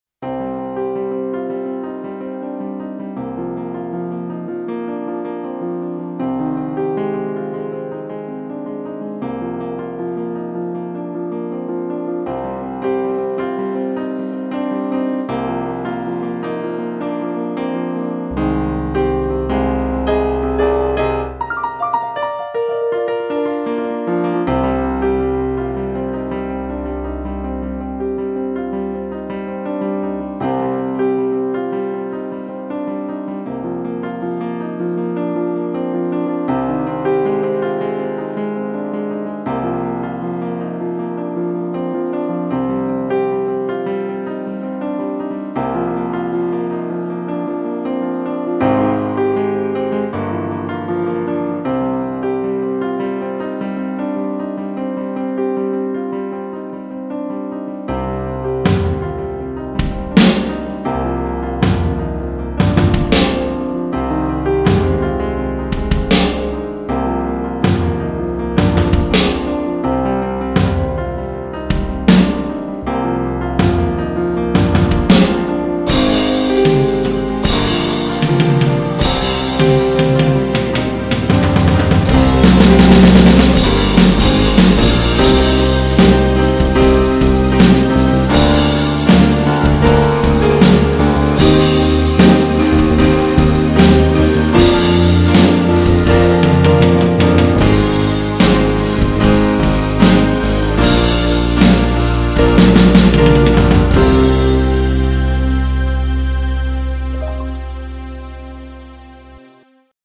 せっかくなので、ピアノに合わせて、エレドラも叩いて、軽くベースも入れて
ピアノとドラムの宅録音源を作り、mixiにアップ。
今聴くと、クリックも使わず、ピアノを先に弾いて、そのあとにドラムを
思い付きで合わせたから、縦軸もずれずれで、ピアノも弾けてないところが
多々ある、とても粗い音源。